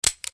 EmptyClip_A.mp3